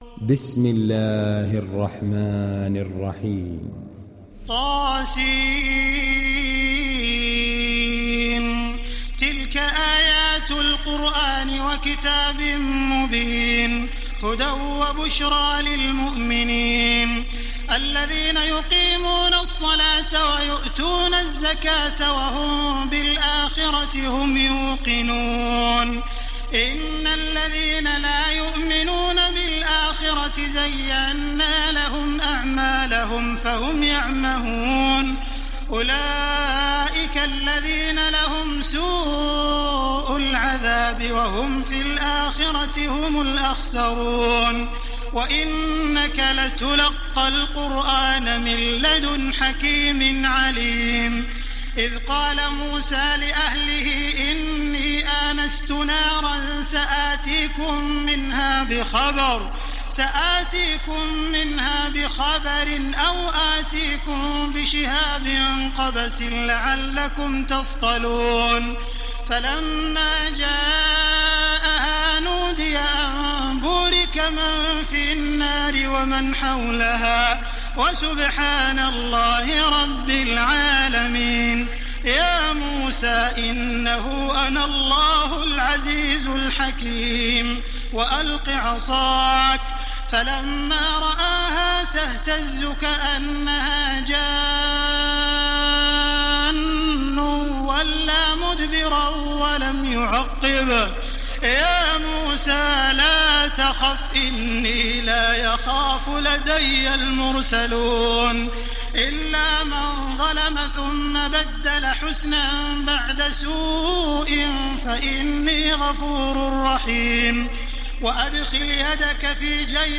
ডাউনলোড সূরা আন-নামল Taraweeh Makkah 1430